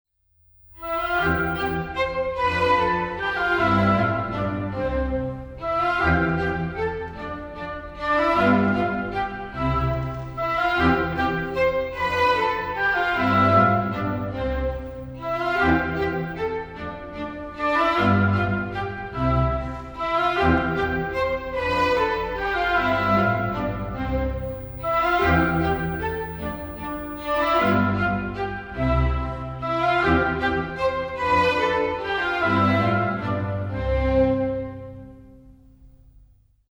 Aufgenommen von der Philharmonie Salzburg - Philharmonie Salzburg
zum Mitsingen (rein instrumental)!